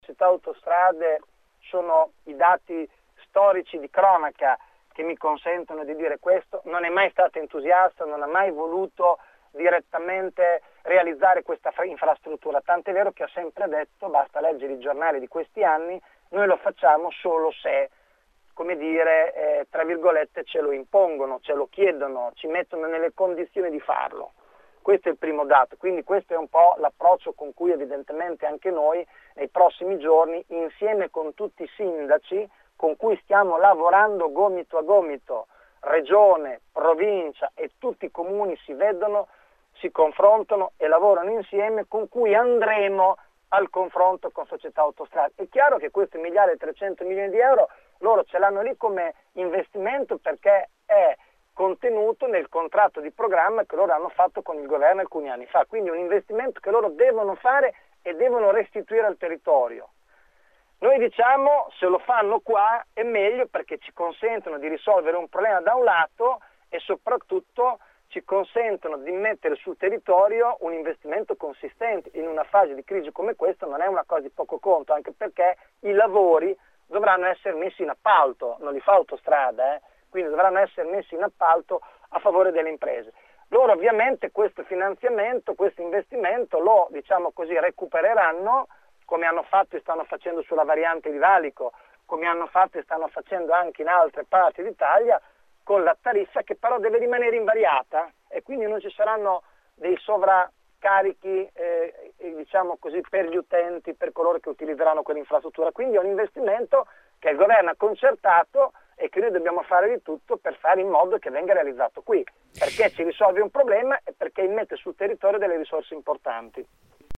Il vice presidente di Palazzo Malvezzi, Giacomo Venturi, questa mattina ai nostri microfoni, ha insistito sull’importanza di portare nel nostro territorio quel miliardo e trecentomila euro che Società Autostrade destinerà all’infrastruttura se si troverà l’accordo sul tracciato: “Dobbiamo fare di tutto perché venga fatto qui”.